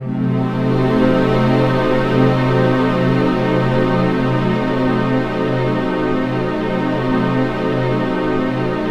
TENSION01.-L.wav